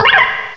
sovereignx/sound/direct_sound_samples/cries/sewaddle.aif at master